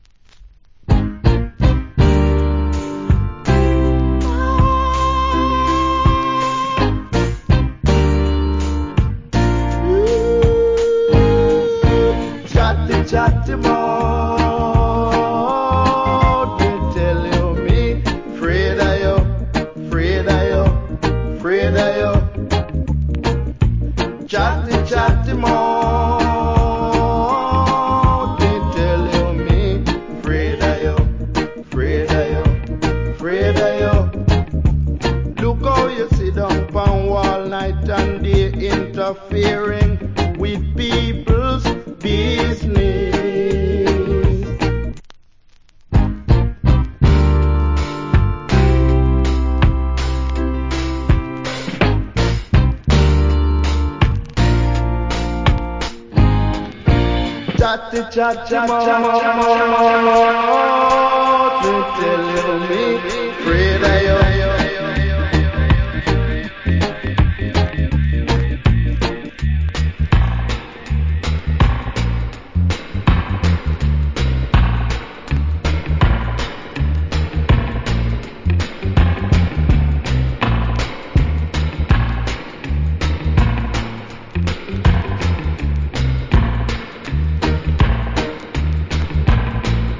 Reggae Vocal. / Nice Dub.